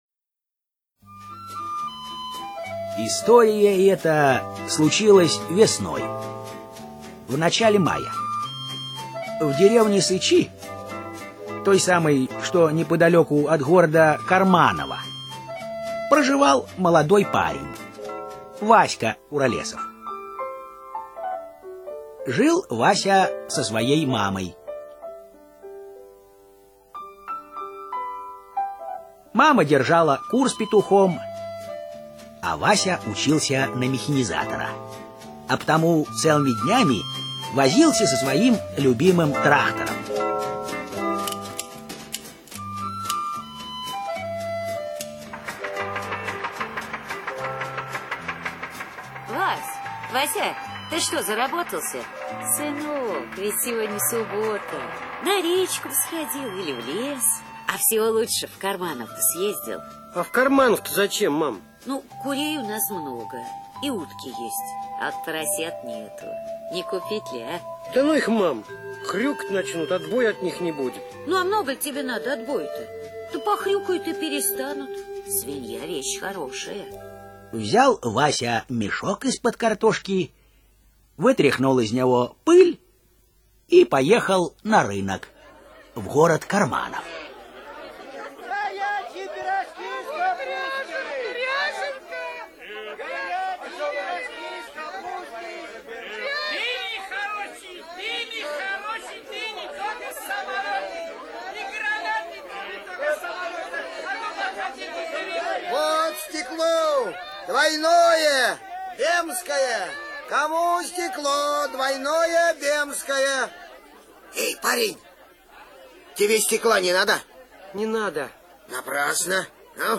Приключения Васи Куролесова - аудио повесть Коваля - слушать онлайн